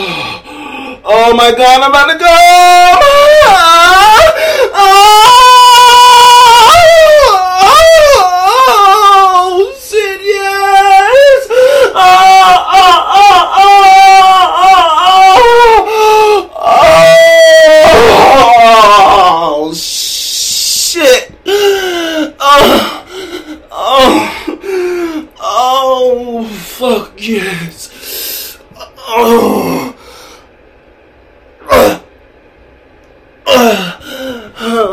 Oh My Gawd Ambatukam Loud Asf Sound Effect Free Download
Oh My Gawd Ambatukam Loud Asf